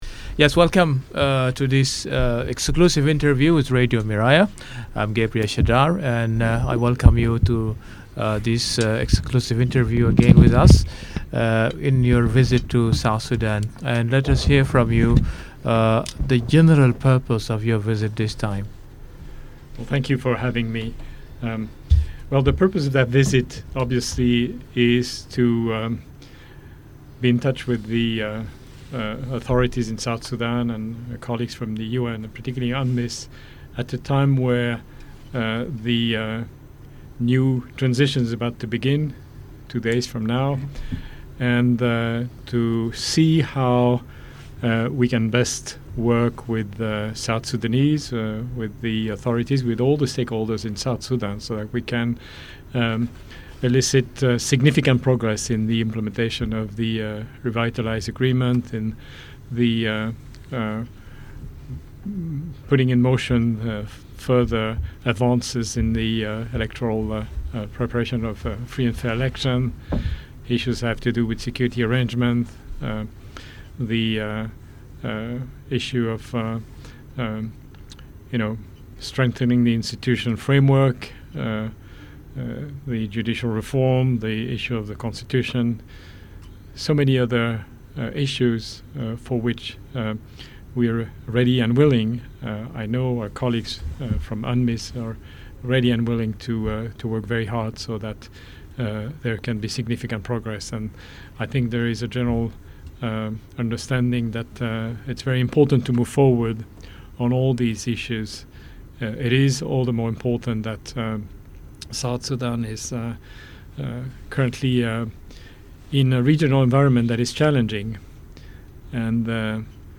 EXCLUSIVE INTERVIEW: UN Peace Operations Chief Highlights Need for Progress in South Sudan Peace Process